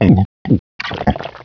1 channel
drowndeath.wav